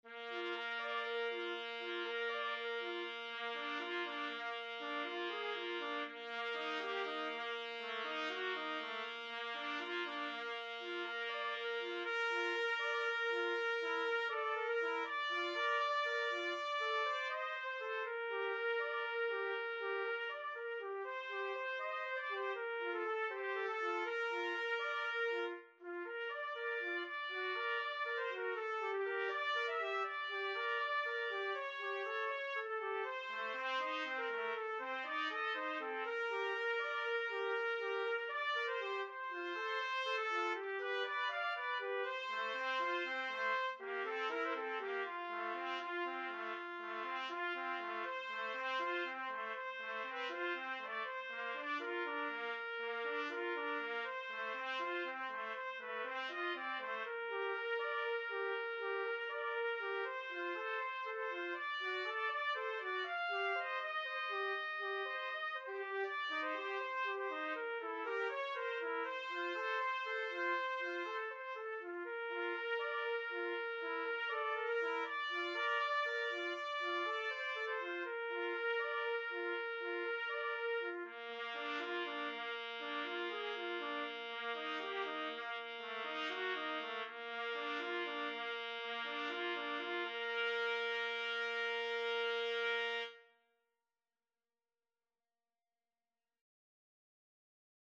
4/4 (View more 4/4 Music)
Andante
Trumpet Duet  (View more Intermediate Trumpet Duet Music)
Classical (View more Classical Trumpet Duet Music)